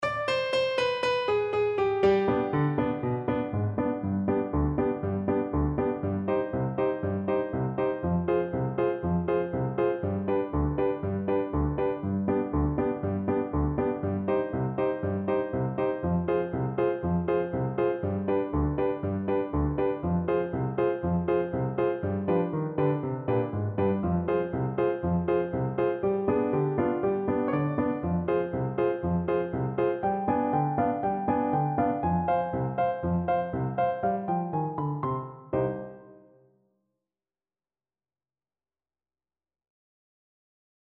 Clarinet
C minor (Sounding Pitch) D minor (Clarinet in Bb) (View more C minor Music for Clarinet )
Allegro (View more music marked Allegro)
Traditional (View more Traditional Clarinet Music)